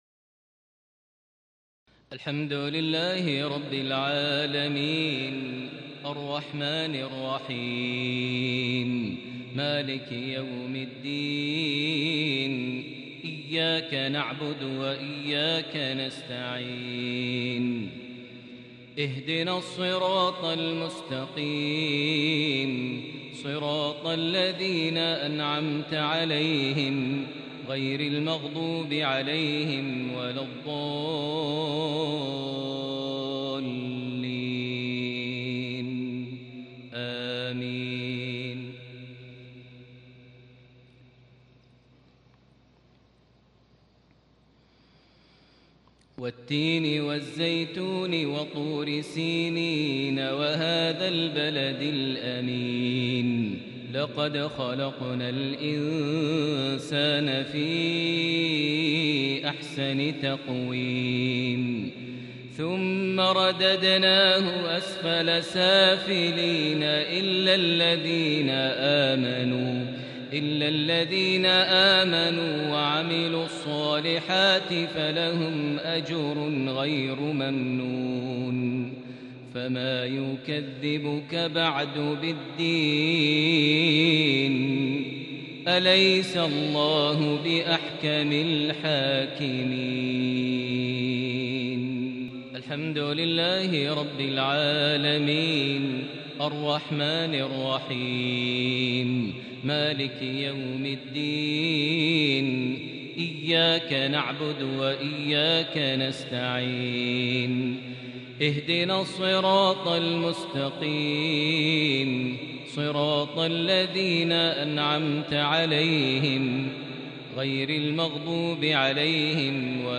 تلاوة كردية فذه لسورتي التين - الماعون مغرب 5 ذو القعدة 1441هـ > 1441 هـ > الفروض - تلاوات ماهر المعيقلي